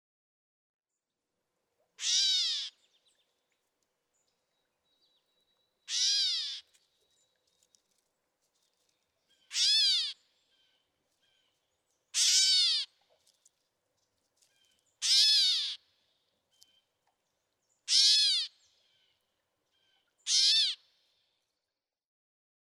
Gray catbird
Meow calls, for which the bird earned its name.
Quabbin Park, Ware, Massachusetts.
023_Gray_Catbird.mp3